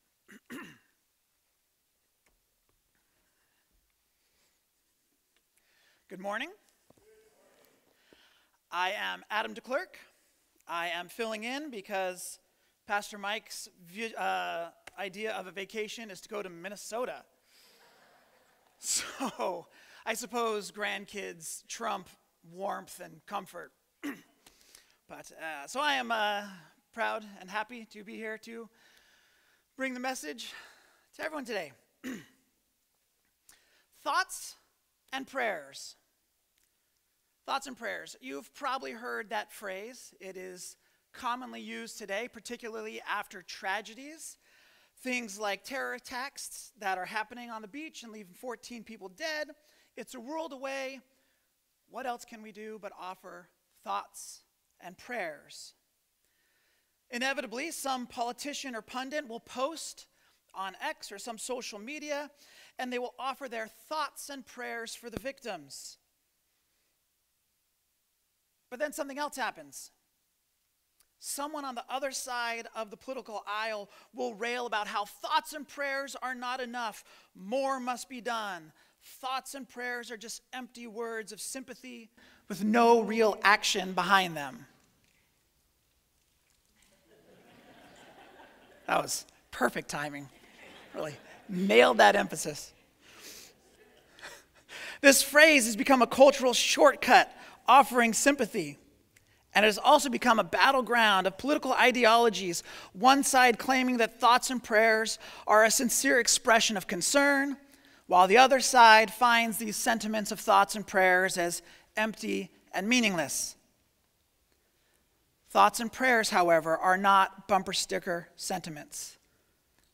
Sermons | Magnolia Baptist Church